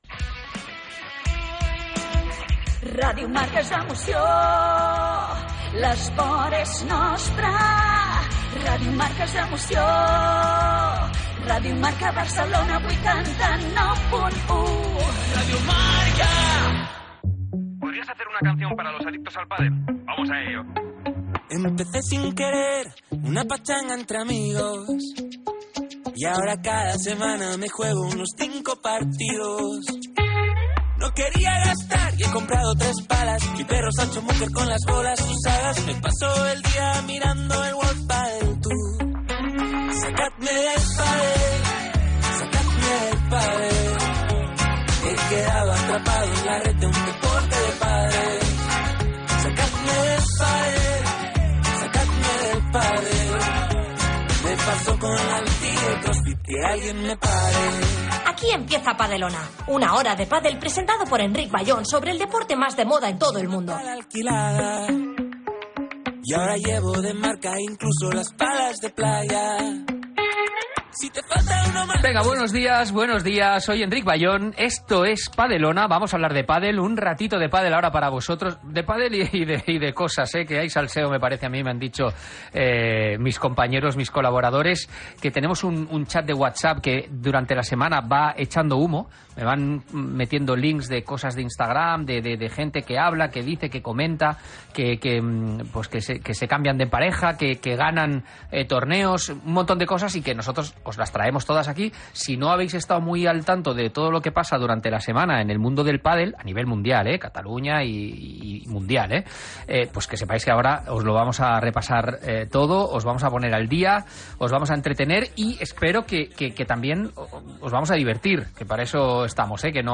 Indicatiu cantat de la ràdio, careta cantada, presentació, publicitat, "La clínica del padel" Gènere radiofònic Entreteniment